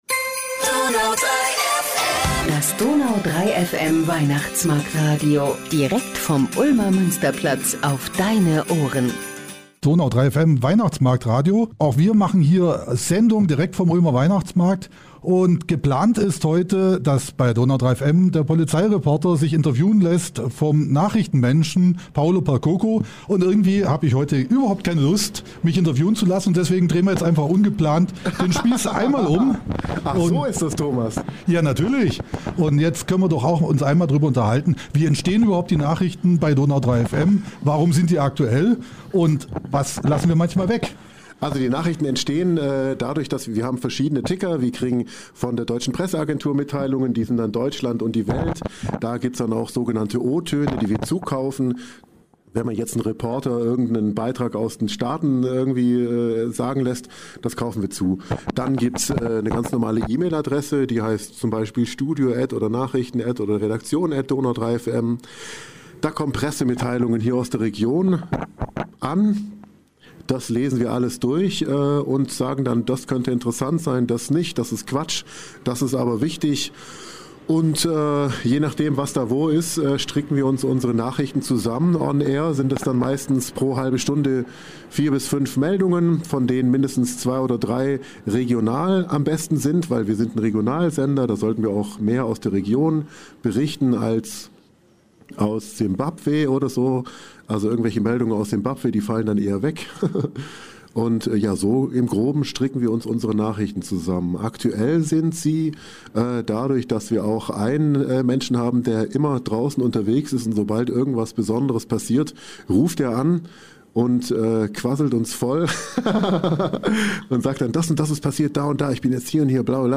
Was dabei rausgekommen ist, das hört ihr in dieser Ausgabe des Ulmer Weihnachtsmarkt-Podcasts, quasi live on tape vom Ulmer Weihnachtsmarkt 2022.